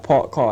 k-backing
Rather than a mildly retracted k in words such as car, come, caught, many younger inner-London speakers have a very retracted plosive, perhaps even a uvular q.
Listen to clips of a young Anglo (= ethnically white) speaker pronouncing the phrases
Note the backed ks, which are typical of such multiculturally-oriented anglos and of non-anglos.
parked_car.wav